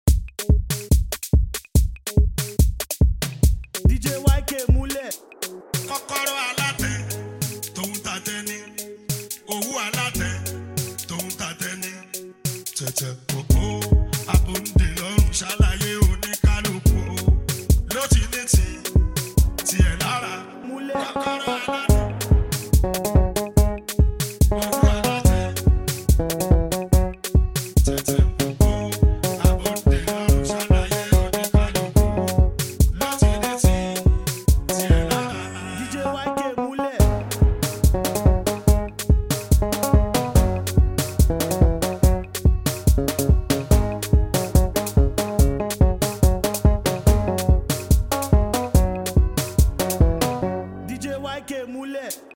Fuji music